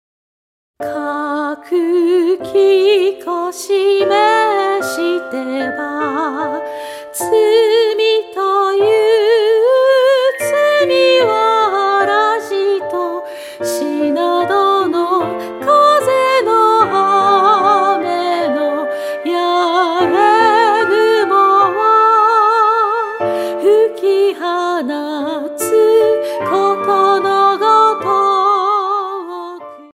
８つの曲を通して、澄んだ歌声と優しい響きが、心に静けさと力を届けます。